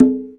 Hand Conga 01.wav